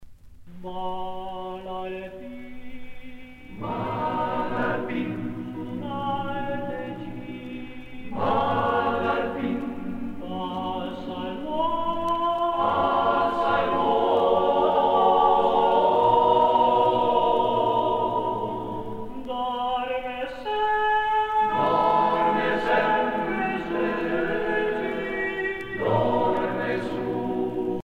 Pièce musicale éditée
Catégorie Pièce musicale éditée